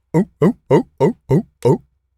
seal_walrus_bark_01.wav